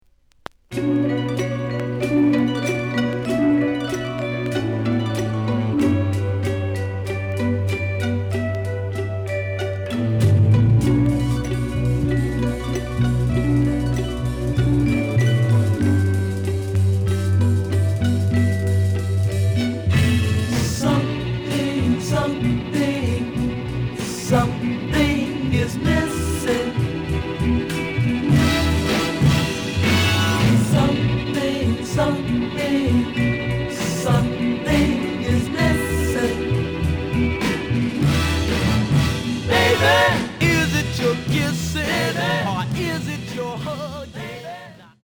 The listen sample is recorded from the actual item.
●Genre: Soul, 60's Soul
●Record Grading: VG (傷は多いが、プレイはまずまず。Plays good.)